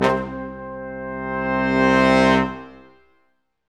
Index of /90_sSampleCDs/Roland LCDP06 Brass Sections/BRS_Quintet sfz/BRS_Quintet sfz